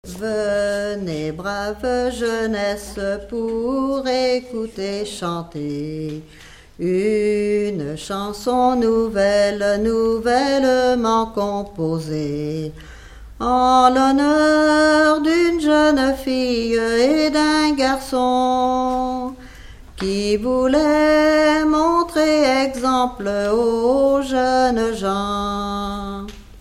Genre strophique
Enquête Arexcpo en Vendée-C.C. Palluau
Pièce musicale inédite